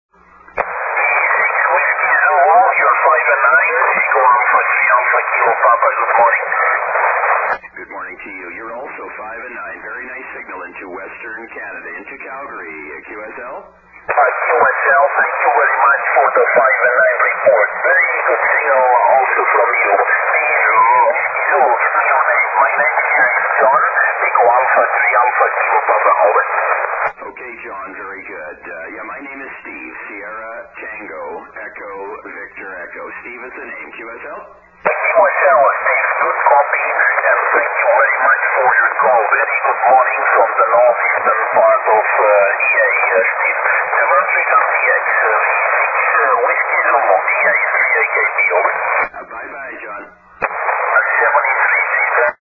If possible use headphones to improve the copy....these recordings are mostly of difficult, lowband QSO's which are often just at the edge of readability.....some signals are very light, but  mostly all Q-5.....afterall...nobody works 80 or 160 without headphones...do they?